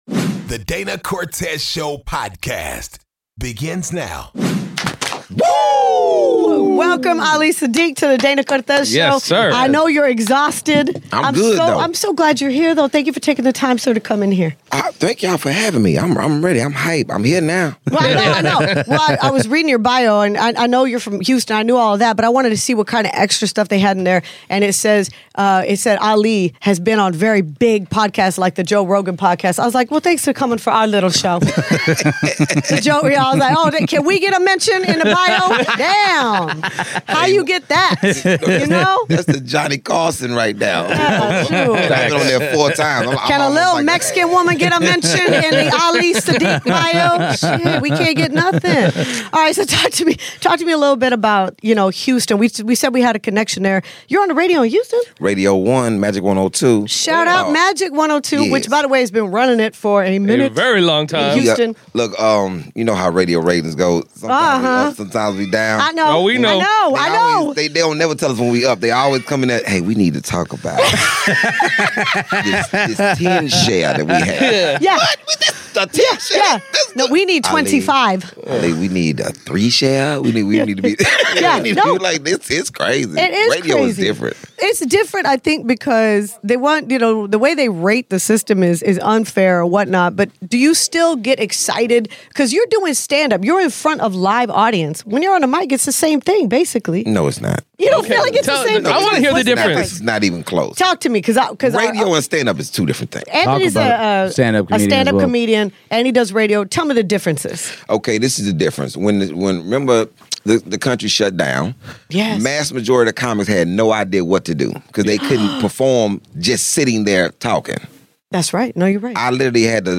DCS Interviews Ali Siddiq